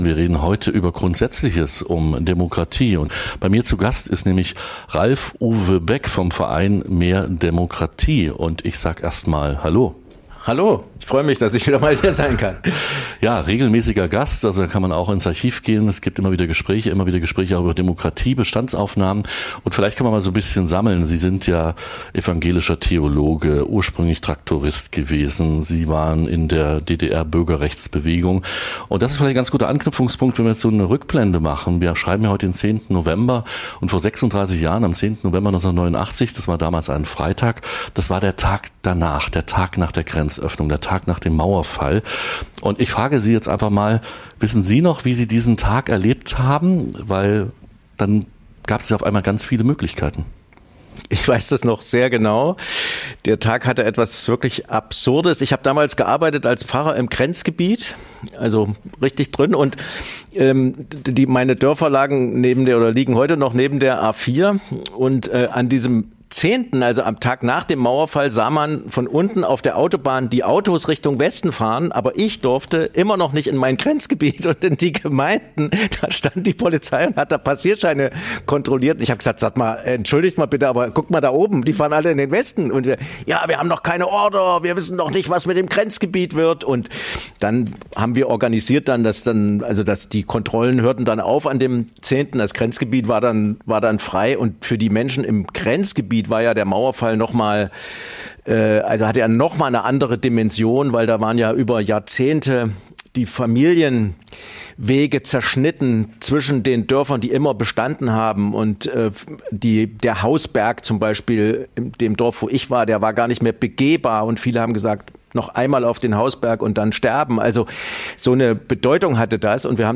Das Gespr�ch